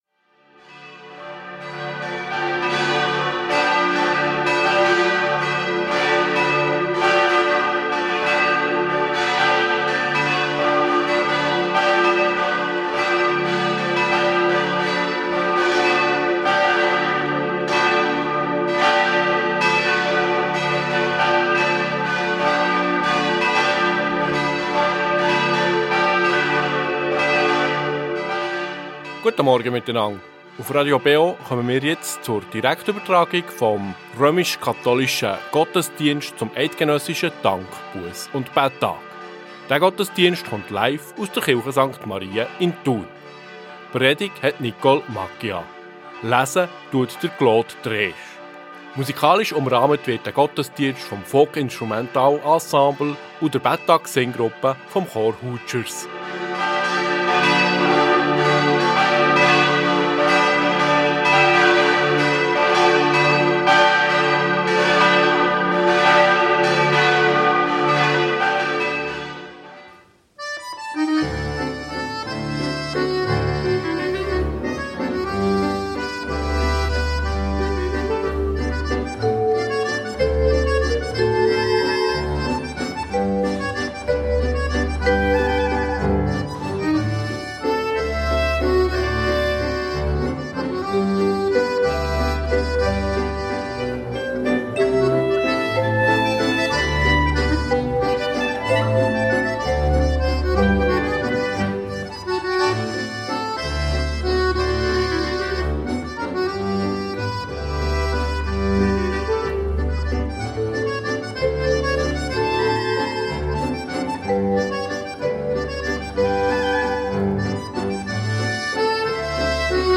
Katholische Kirche St. Marien Thun ~ Gottesdienst auf Radio BeO Podcast